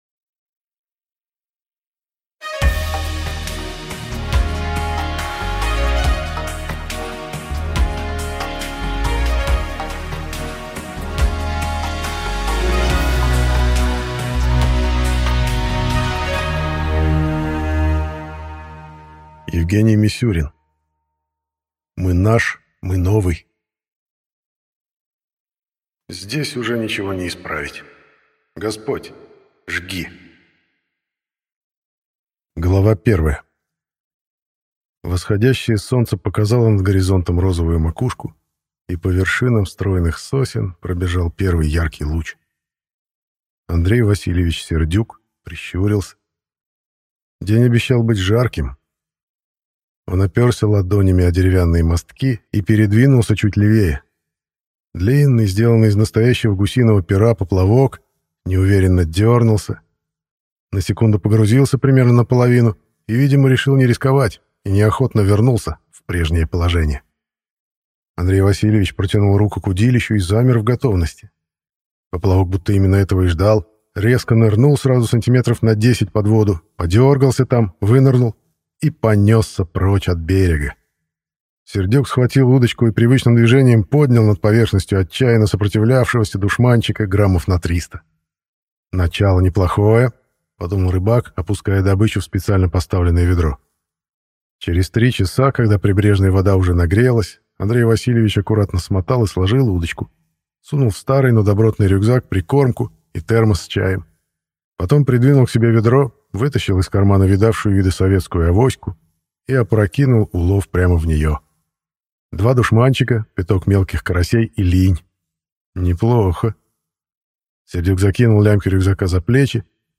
Аудиокнига Мы наш, мы новый…